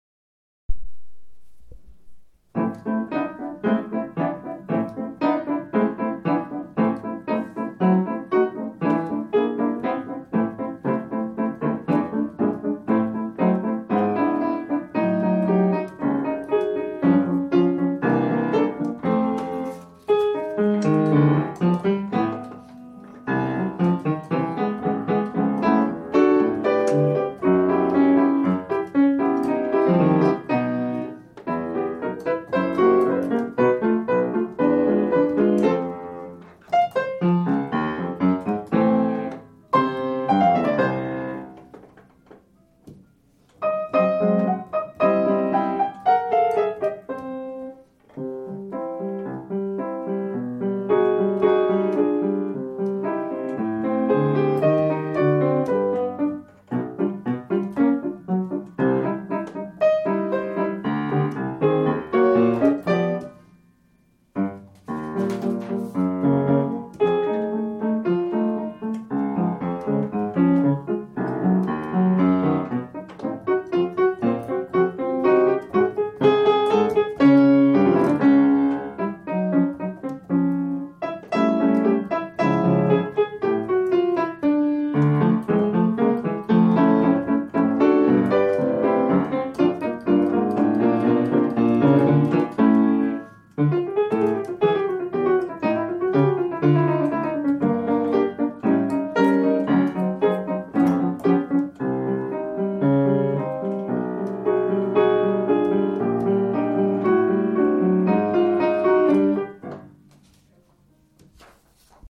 Dring 3rd Movement 1st Half Piano Only